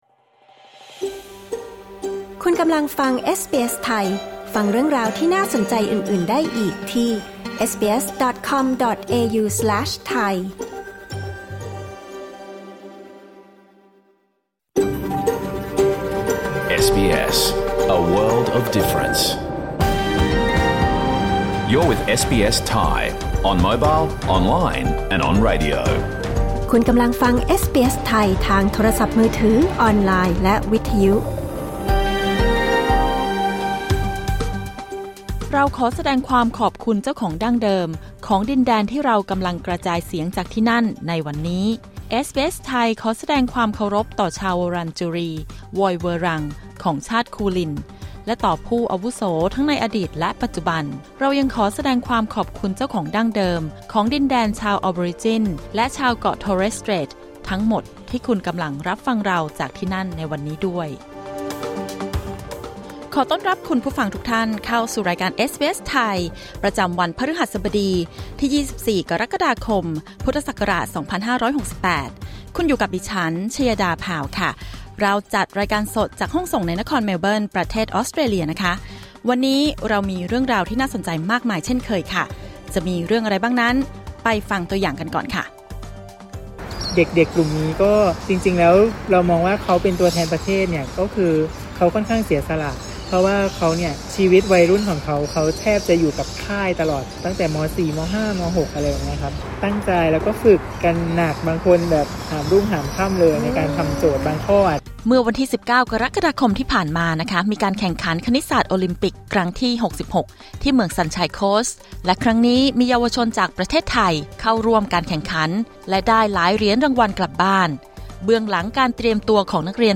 รายการสด 24 กรกฎาคม 2568